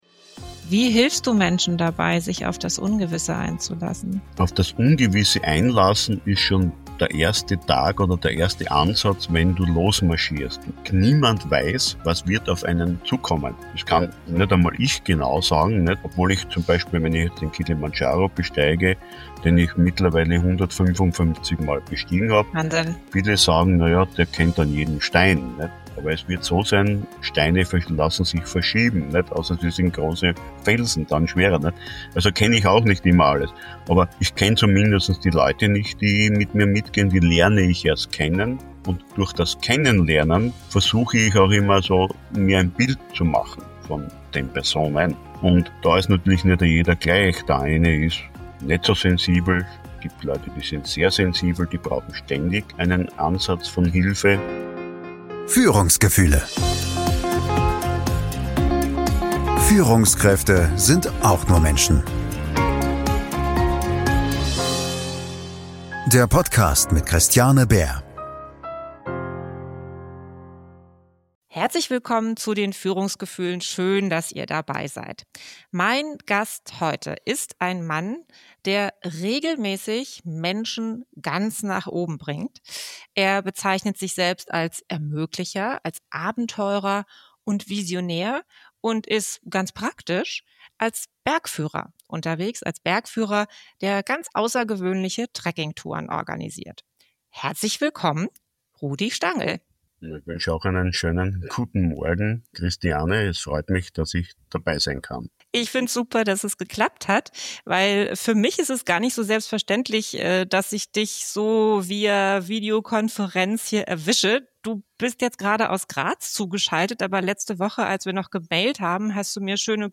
Diese Folge ist ruhig und kraftvoll.